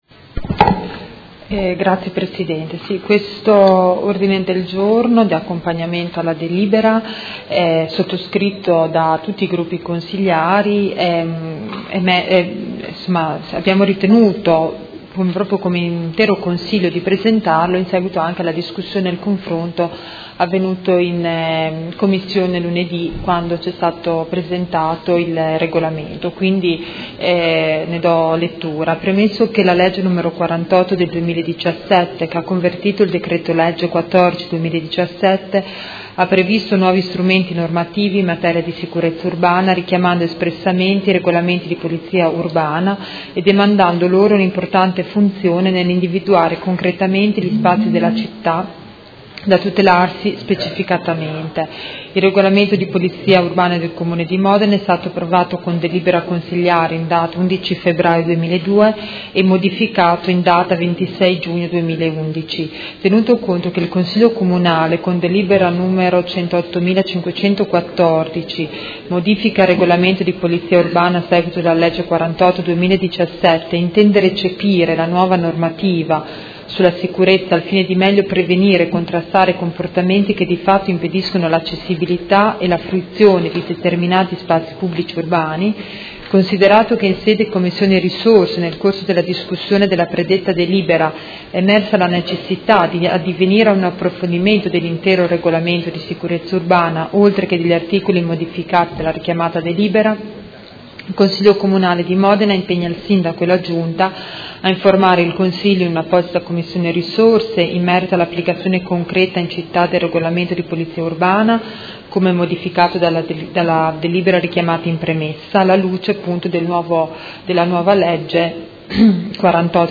Seduta del 20/07/2017 Presenta Odg n.11949: Regolamento Polizia Urbana